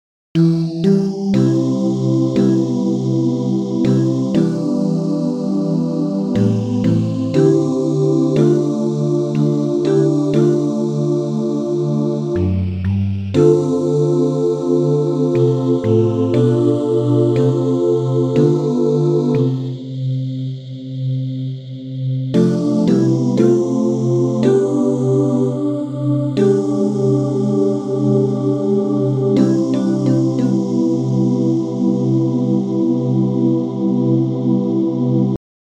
Key written in: C Major
How many parts: 4
Comments: Not meant to be rushed at all!
All Parts mix: